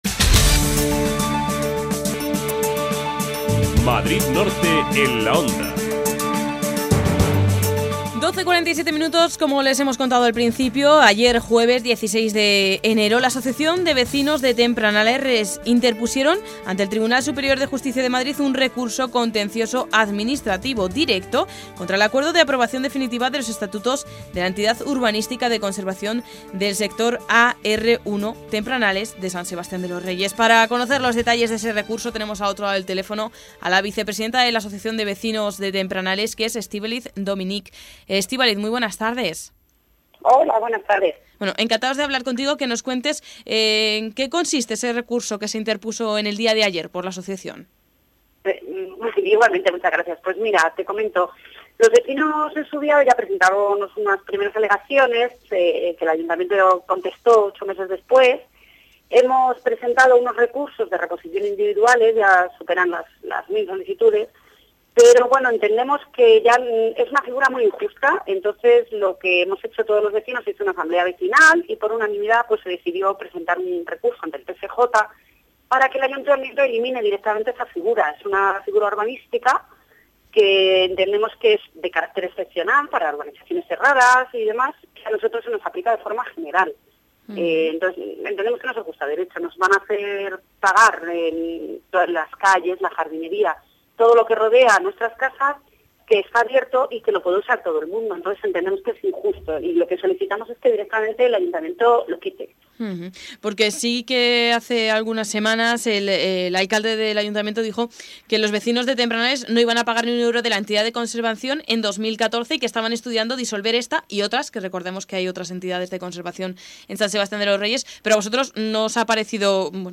Entrevista de la Vicepresidenta en Onda Cero